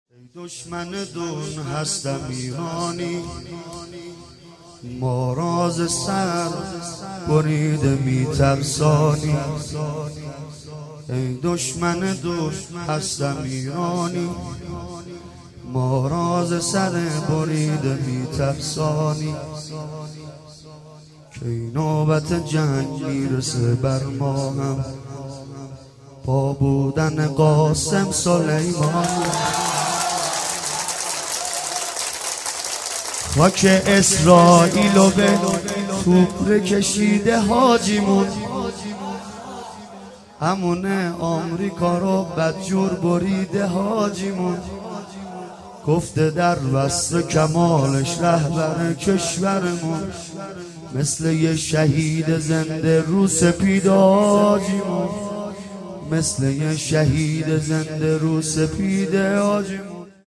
مداحی در مورد حاج قاسم سلیمانی
مداح:سیدرضا نریمانی